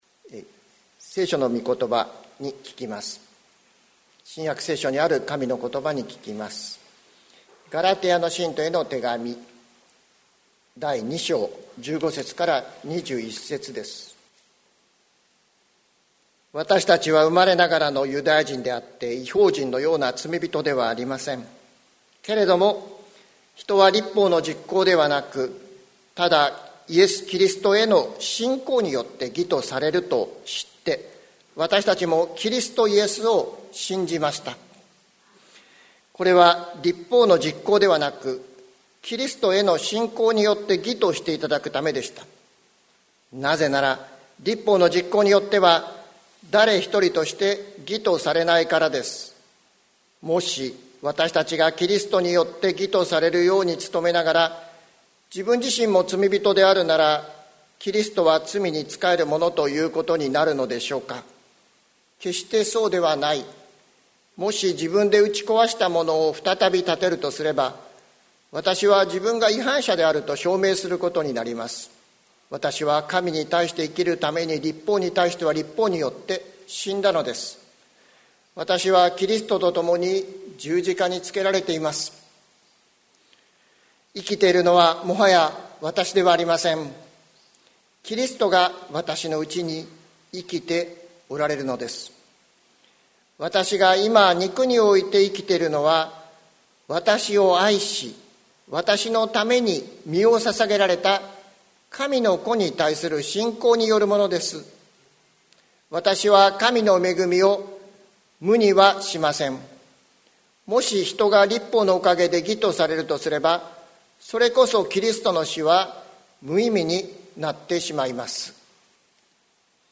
2021年05月16日朝の礼拝「神の恵みを無にしません」関キリスト教会
説教アーカイブ。